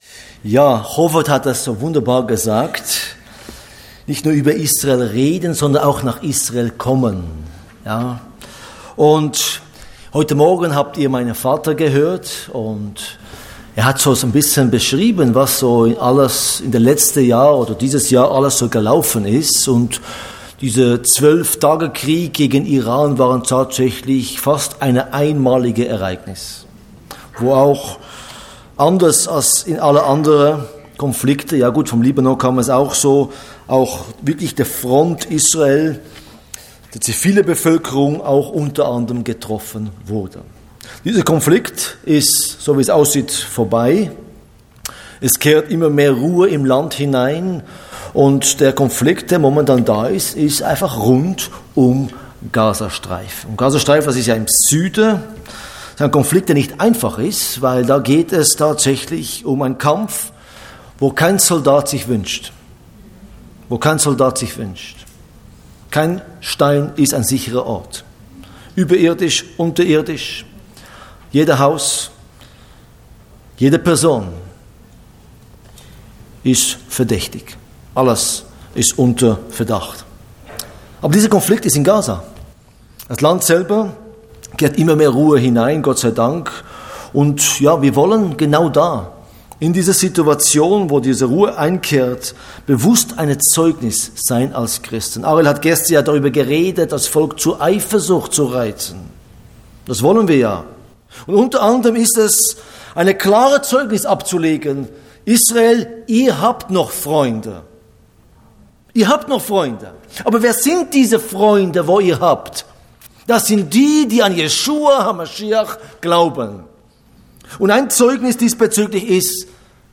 Botschaft Zionshalle